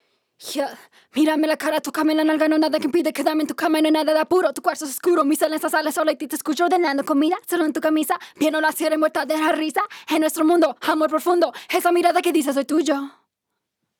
AutoTune-Hard-Tuned-TUNED-1.wav